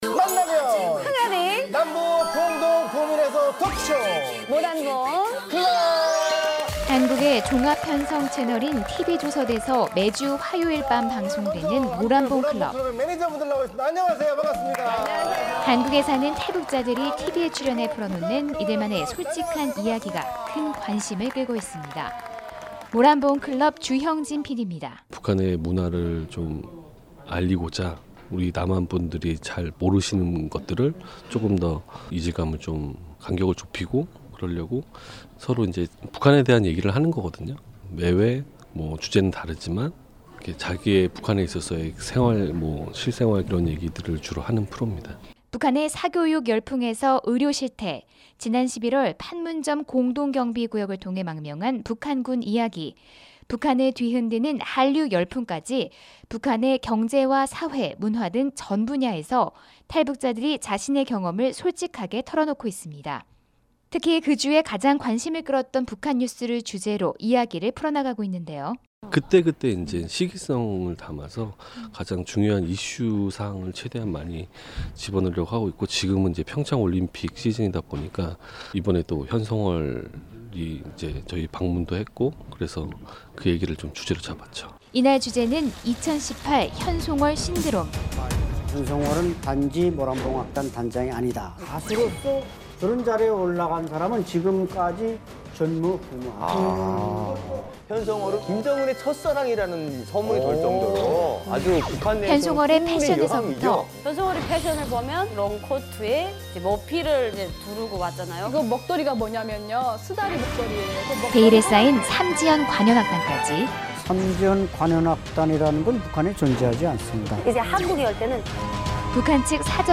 [특파원 리포트] 탈북자 출연 한국 TV 프로그램 “남북관계 개선에 도움 되길”